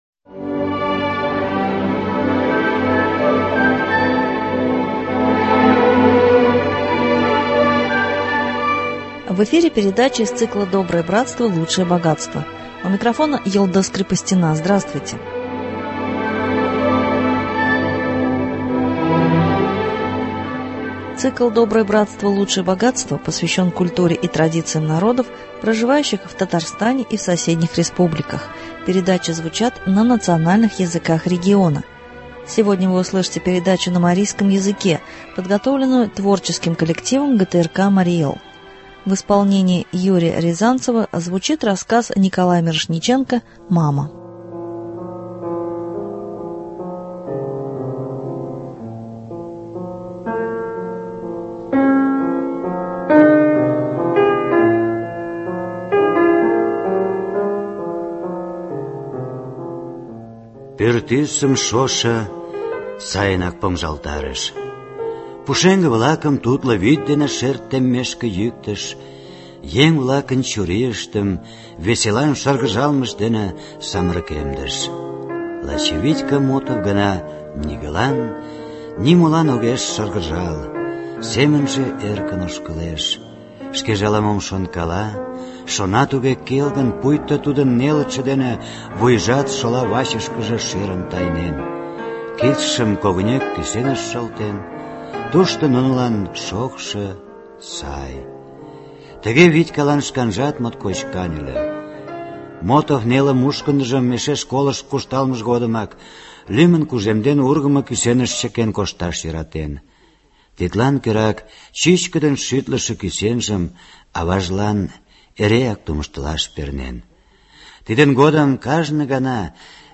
Цикл посвящен культуре и традициям народов, проживающих в Татарстане и в соседних республиках, передачи звучат на национальных языках региона. Сегодня прозвучит поэтическая передача